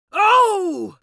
دانلود آهنگ دعوا 24 از افکت صوتی انسان و موجودات زنده
دانلود صدای دعوا 24 از ساعد نیوز با لینک مستقیم و کیفیت بالا
جلوه های صوتی